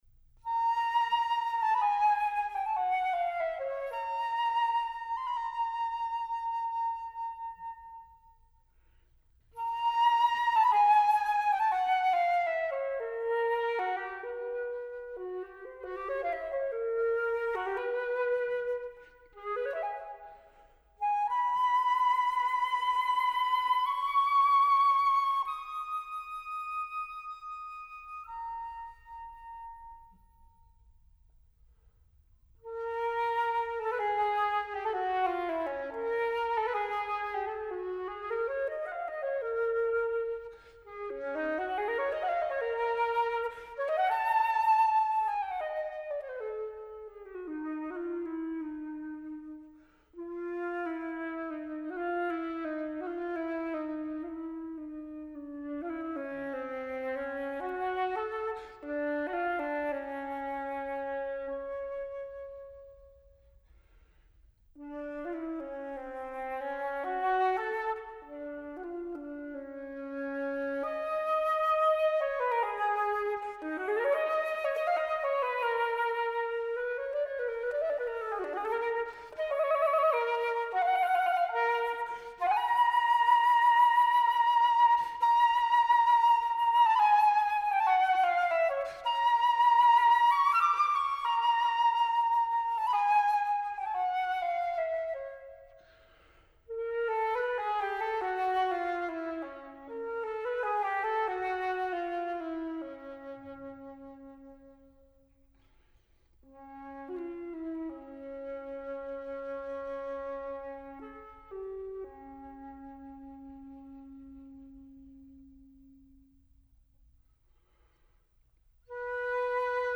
Voicing: Solo Flute